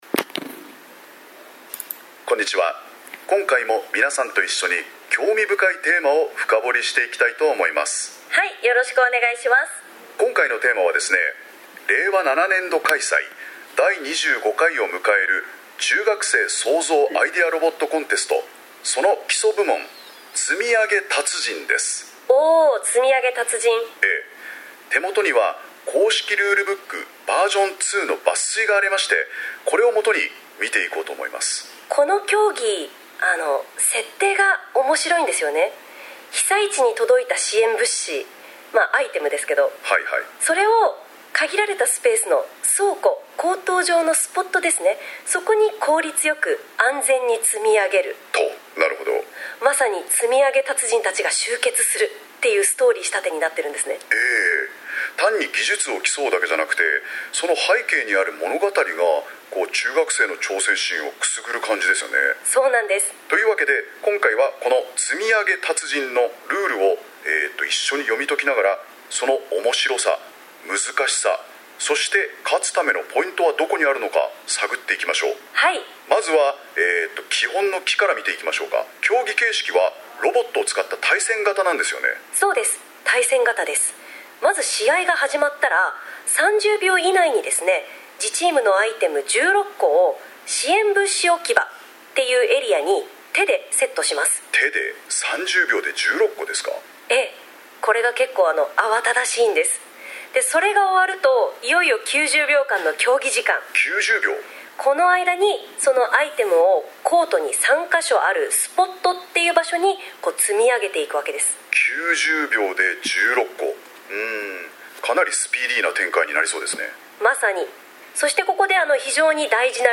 生成AIによるルールや見所の解説・・・R7 ロボコン基礎部門生成AIによるルール等の解説
R7-ロボコン基礎部門生成AIによるルール等の解説.mp3